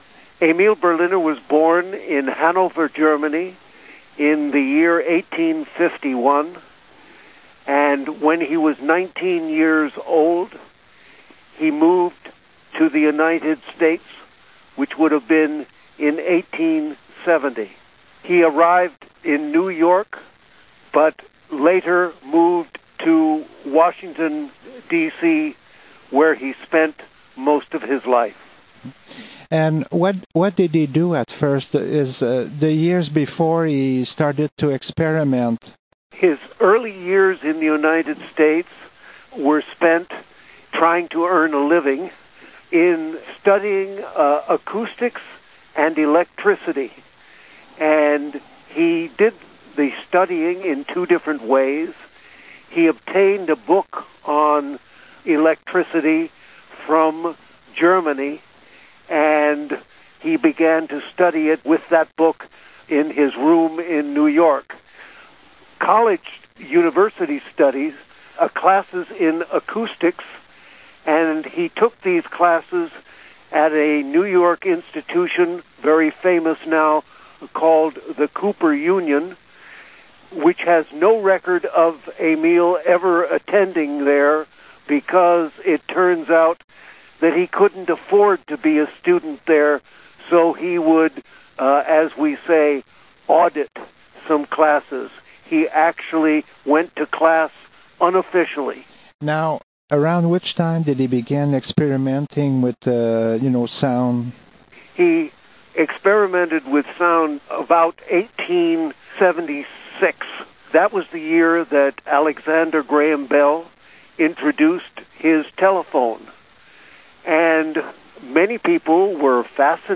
Phone interview recorded at Studio Victor, Montreal (QC) Canada.